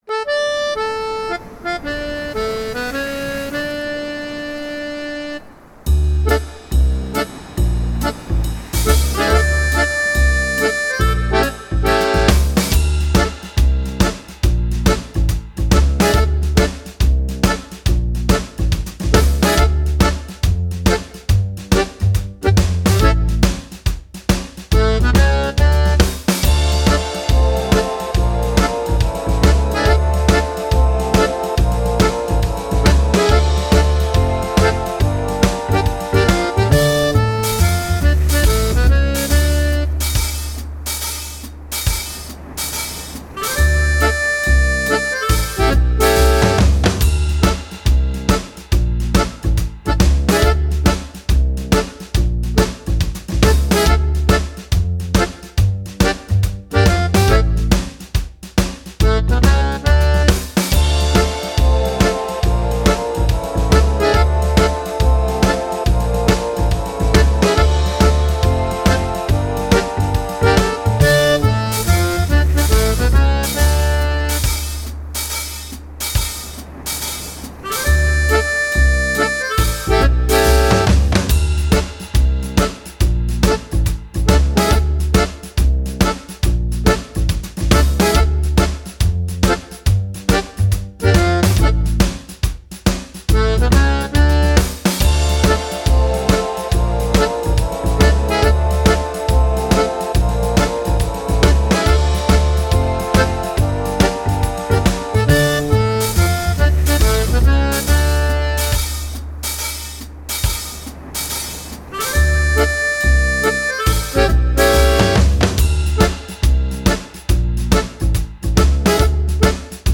Übungsaufnahmen - Sie hiess Mary Ann
Sie hiess Mary Ann (Playback)